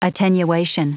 Prononciation du mot attenuation en anglais (fichier audio)
Prononciation du mot : attenuation